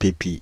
Ääntäminen
Ääntäminen Paris: IPA: [pə.pi] Tuntematon aksentti: IPA: /pe.pi/ Haettu sana löytyi näillä lähdekielillä: ranska Käännös Substantiivit 1. пипка Pépie on sanan pépier taipunut muoto.